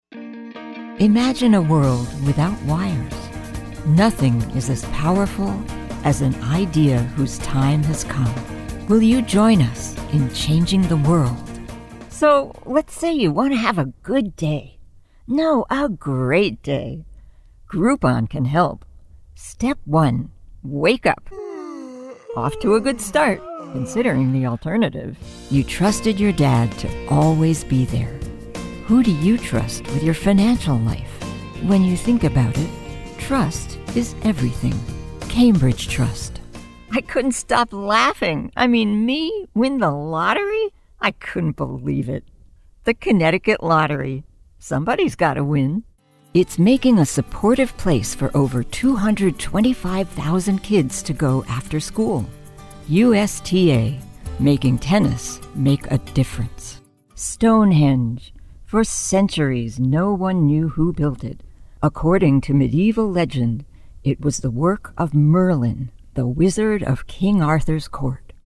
American Voice Over Talent
Adult (30-50)
Our voice over talent record in their professional studios, so you save money!